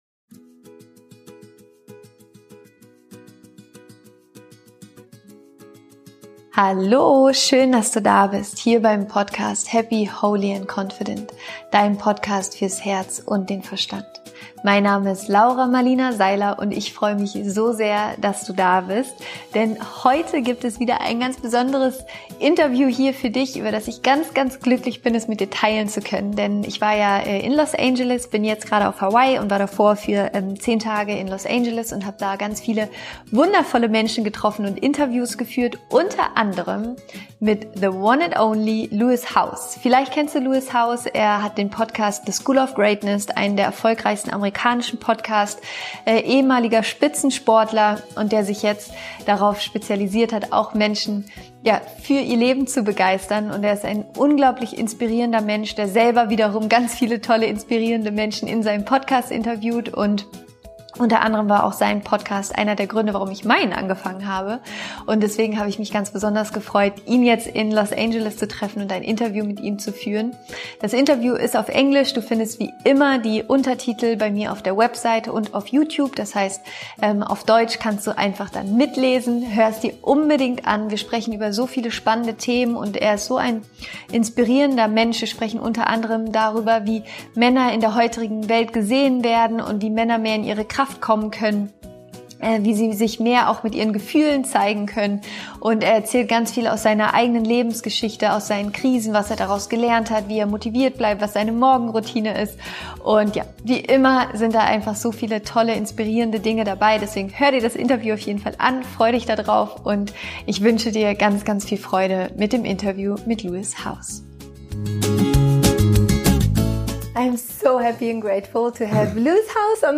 Wie du deine größten Rückschläge positiv für dich nutzen kannst – Interview Special mit Lewis Howes happy, holy & confident® Dein Podcast fürs Herz und den Verstand Download ** Das Interview ist auf Englisch.
** Ich hatte vor drei Wochen die große Freude Lewis Howes in seinem Podcast-Studio in LA zu treffen.